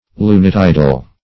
Search Result for " lunitidal" : The Collaborative International Dictionary of English v.0.48: Lunitidal \Lu"ni*tid`al\, a. Pertaining to tidal movements dependent on the moon.